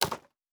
pgs/Assets/Audio/Fantasy Interface Sounds/UI Tight 15.wav at master
UI Tight 15.wav